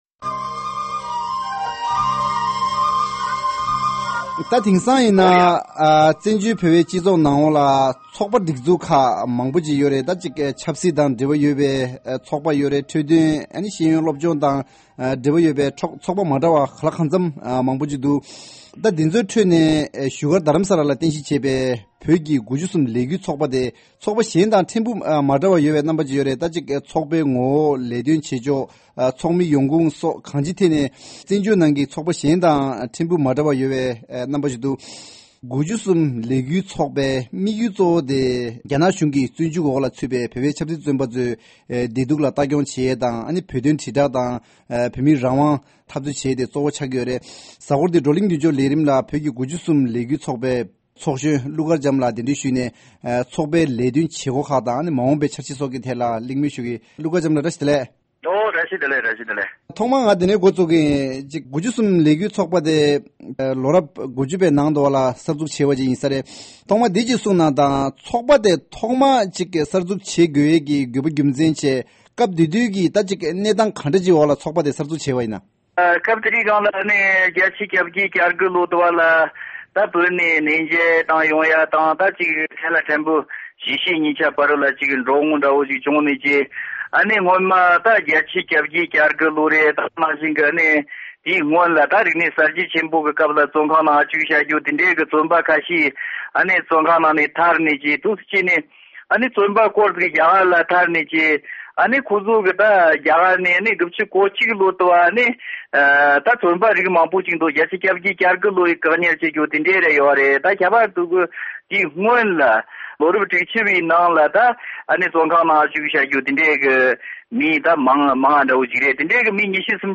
གླིང་མོལ་ཞུས་པ་ཞིག་གསན་གྱི་རེད།